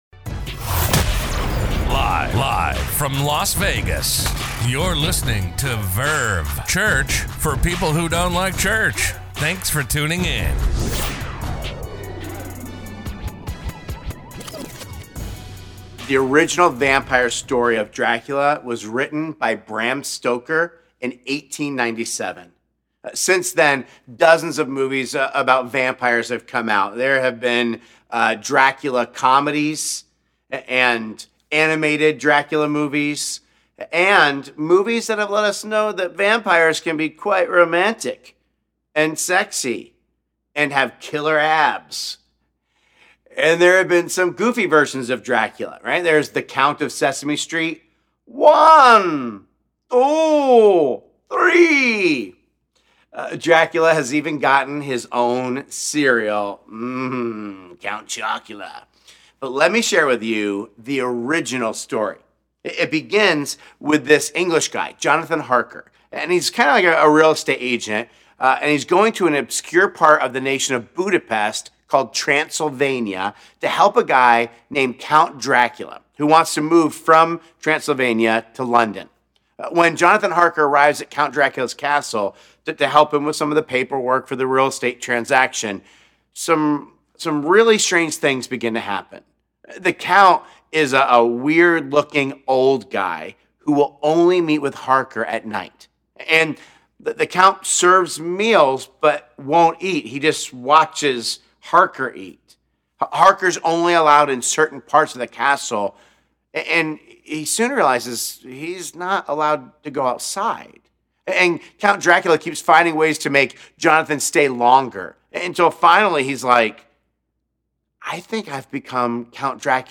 A message from the series "I Believe In Monsters.."